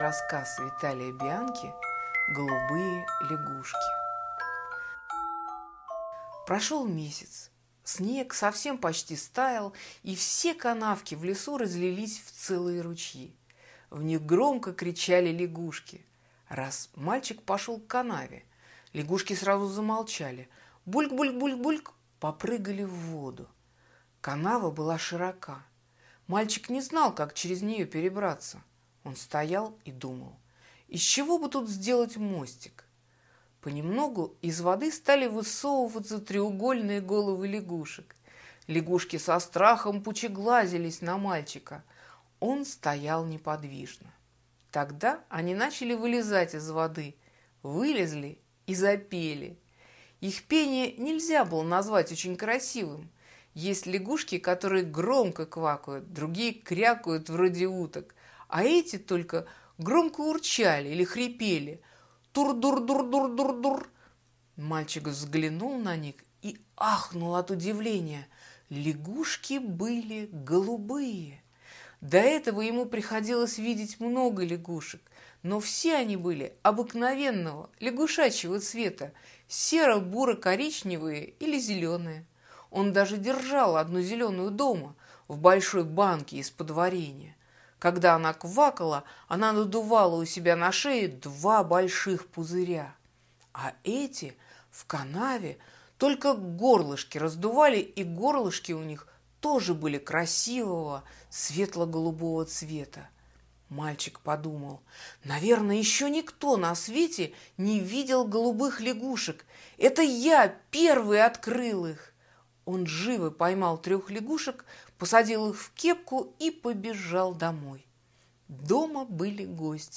Аудиорассказ «Голубые лягушки»